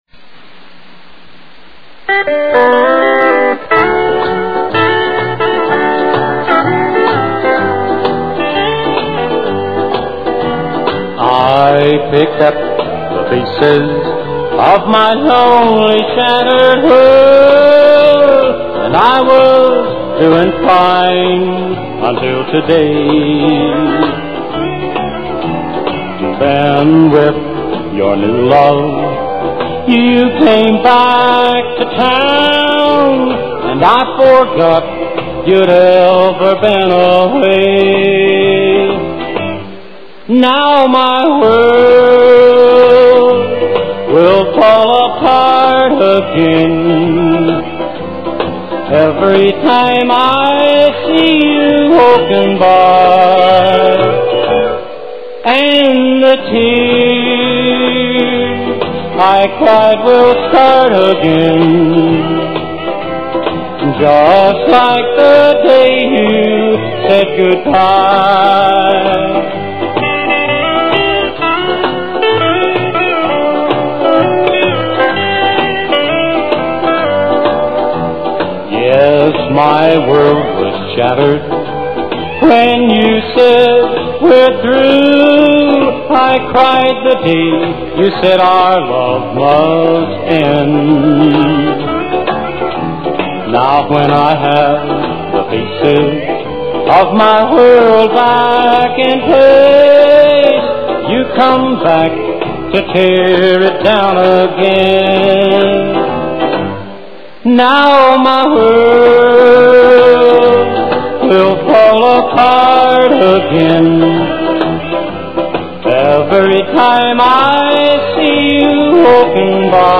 A Tribute To Old Time Country Music
plays some very impressive guitar along with his singing